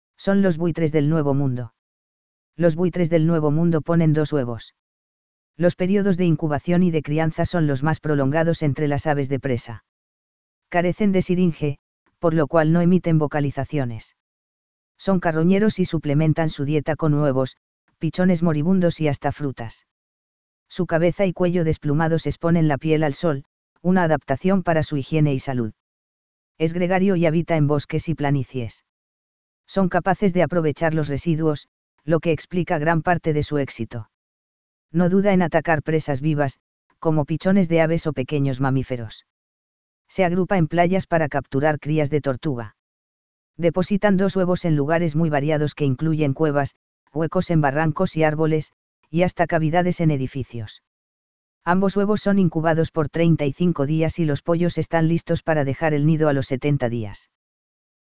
Buitrecabezanegra.mp3